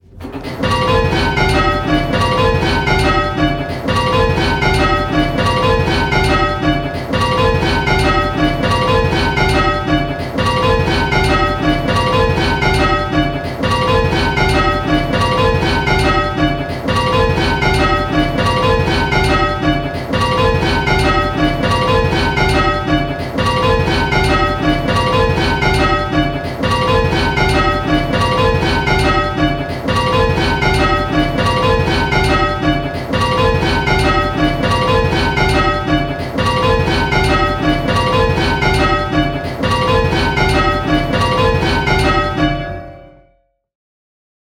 Hearing 6 bells...
We know that hearing specific bells can be difficult, so in these clips we have kept things very simple… in each clip 1 bell is ringing early… and consistently so, at both hand and backstroke.
Click the play button and listen to the rhythm of the bells…. the rhythm will sound a bit lumpy!
Rounds-5-early.m4a